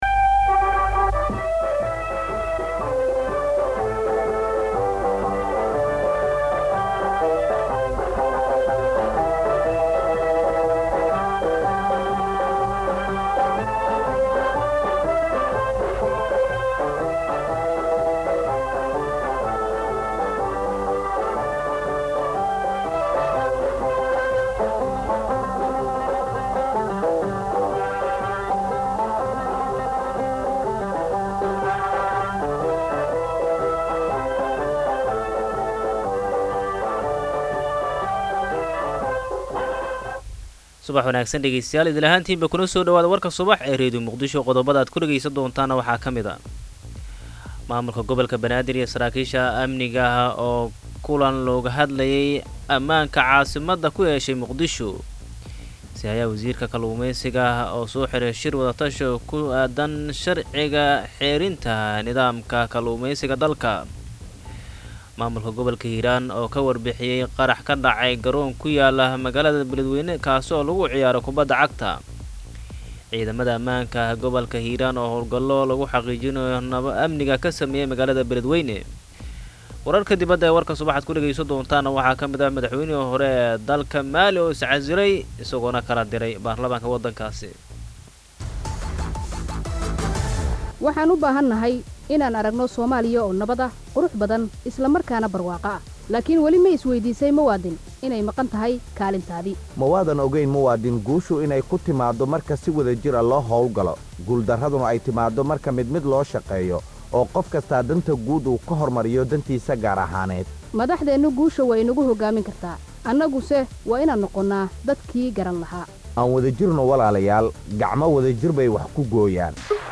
LIVE STREAM KUBIIR ASXAABTEENNA DHAGAYSO WARKA Warka Subaxnimo Warka Duhurnimo Warka Fiidnimo